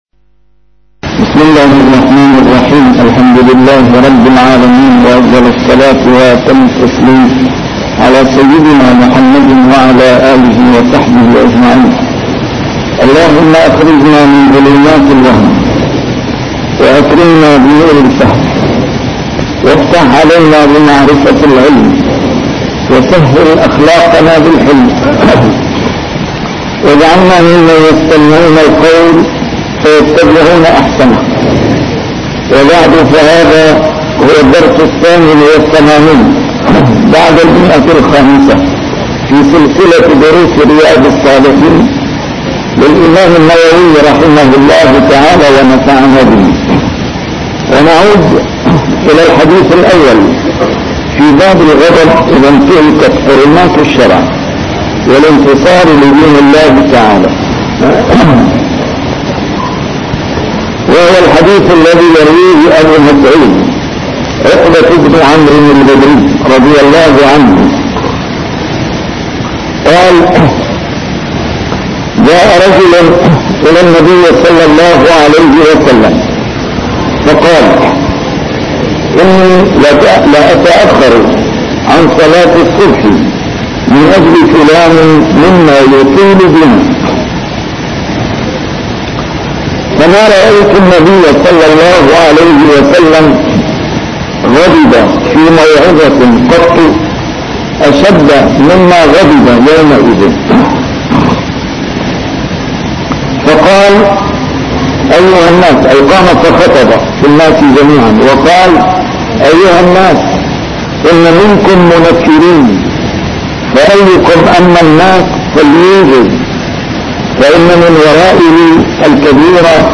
A MARTYR SCHOLAR: IMAM MUHAMMAD SAEED RAMADAN AL-BOUTI - الدروس العلمية - شرح كتاب رياض الصالحين - 588- شرح رياض الصالحين: الغضب